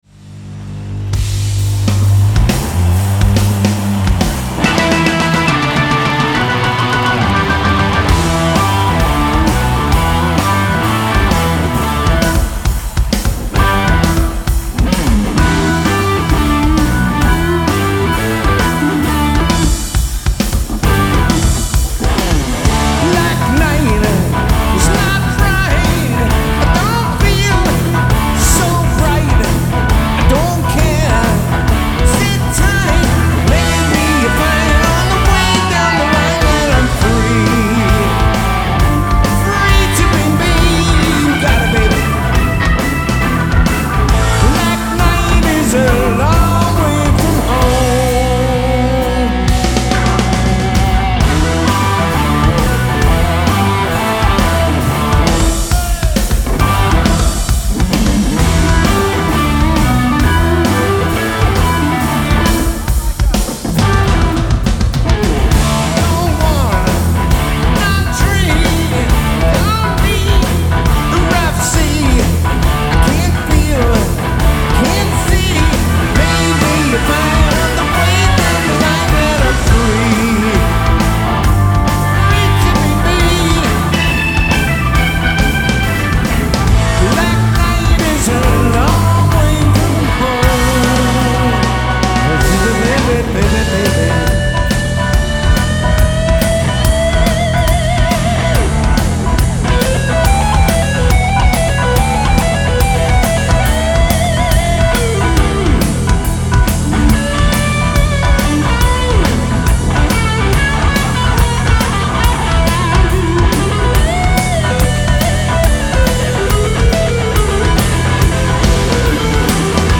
Genre : Metal